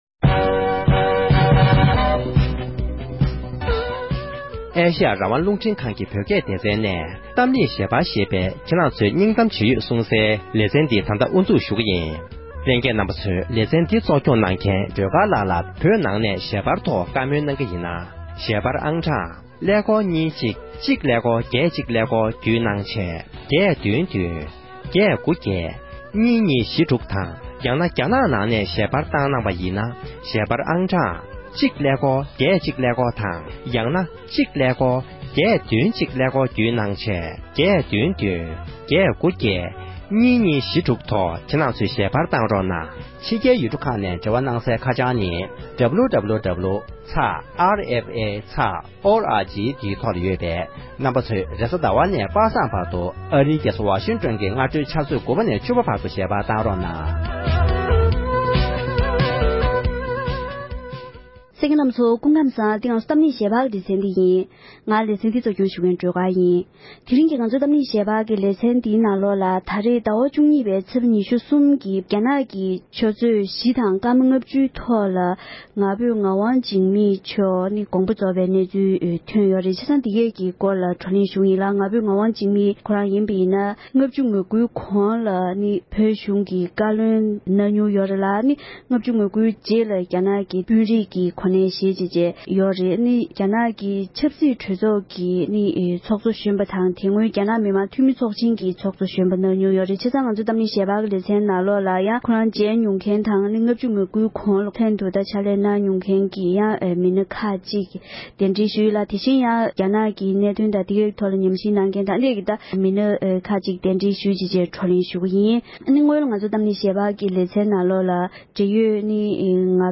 ༄༅༎དེ་རིང་གི་གཏམ་གླེང་ཞལ་པར་གྱི་ལེ་ཚན་ནང་དུ་ཕྱི་ཟླ་བཅུ་གཉིས་པའི་ཚེས་ཉེར་གསུམ་ཉིན་བཀའ་ཟུར་ང་ཕོད་ངག་དབང་འཇིགས་མེད་མཆོག་དགོངས་པ་རྫོགས་པའི་གནས་ཚུལ་ཐོག་ནས་འབྲེལ་ཡོད་མི་སྣ་དང་ལྷན་དུ་བགྲོ་གླེང་ཞུས་པའི་དུམ་མཚམས་དང་པོར་གསན་རོགས༎